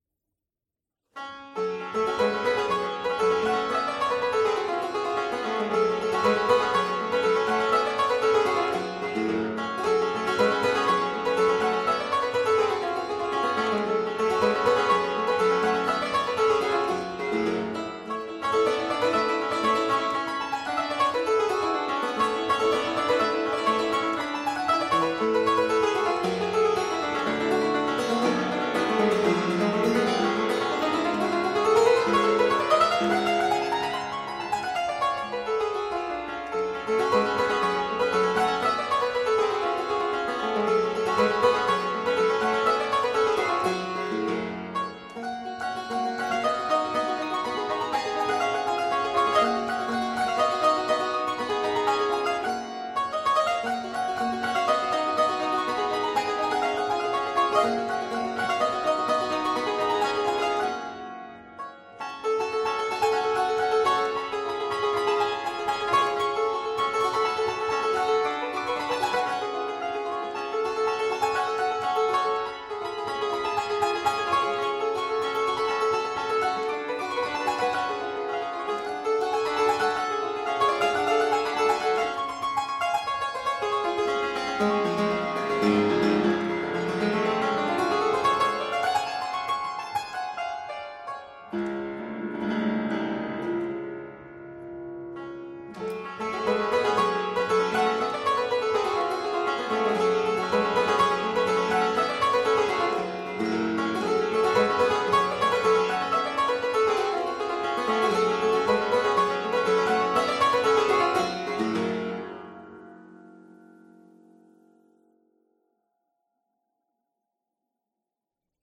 Rondo-Allegro, undated ca. 1775-1785: from Troisième recueil de sonates pour le piano forte avec accompag.t
PerformerThe Raritan Players
Subject (lcsh) Sonatas